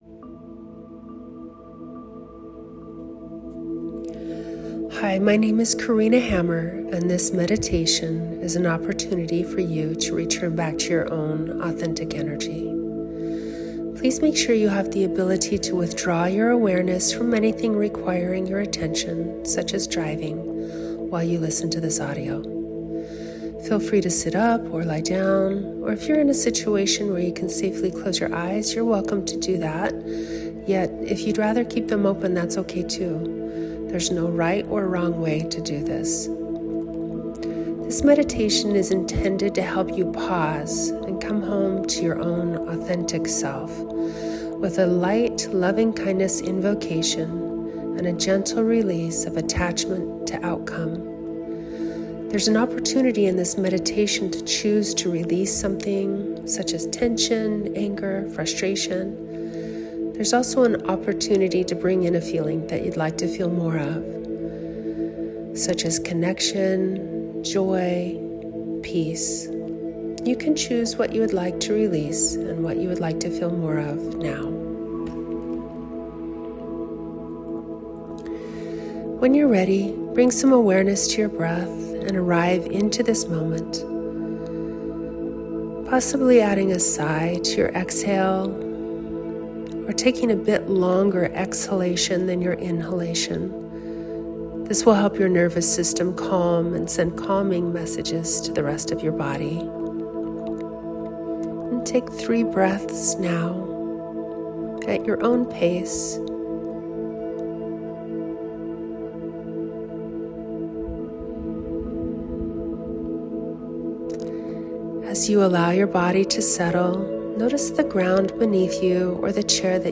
I recently recorded an 11-minute meditation called Return to Your Own Authentic Energy.